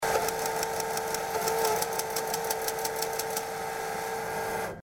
money_in_finish.mp3